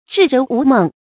至人无梦 zhì rén wú mèng
至人无梦发音